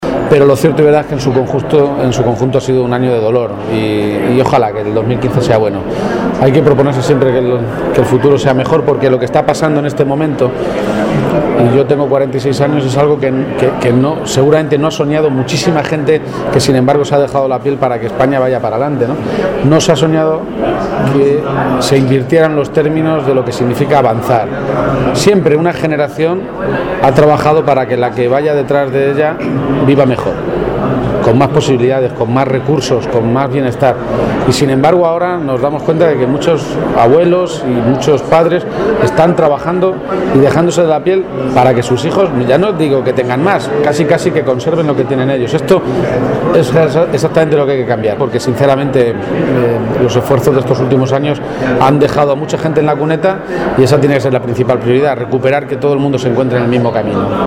El candidato socialista a la Presidencia de Castilla-La Mancha participó anoche en la cena solidaria del PSOE de Guadalajara
García-Page hizo estas declaraciones durante la cena de Navidad del PSOE de esta provincia, a la que asistieron cerca de 400 personas militantes y simpatizantes, y que nuevamente tuvo un carácter solidario con la recogida de juguetes que serán entregados a familias con dificultades económicas.